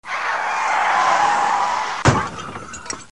Accident de voiture
Bruit de freinage de voiture, avec un accident (bruit de collision de voiture) et le bruit de bris de verre (phares).